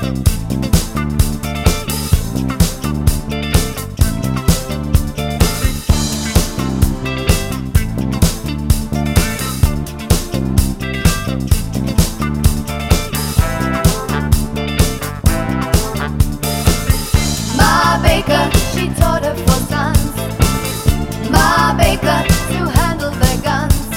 Female Solo Disco 4:38 Buy £1.50